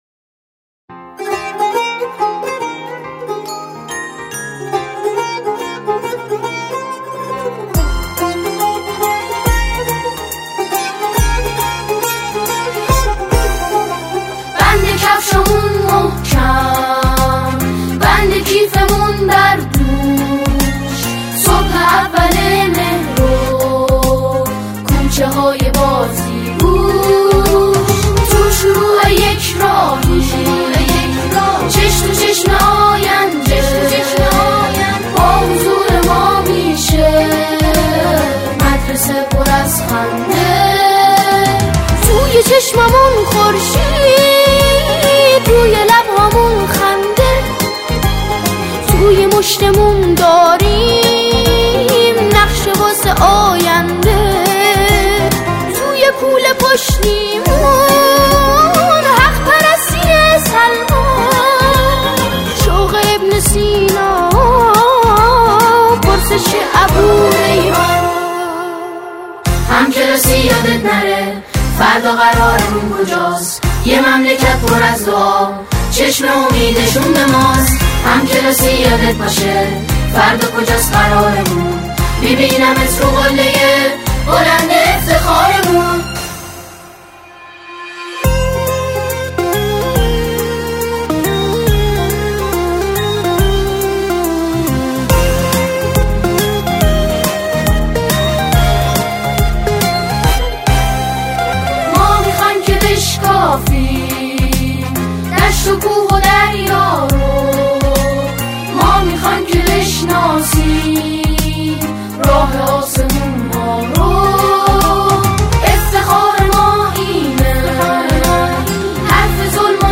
سرودهای دانش آموزی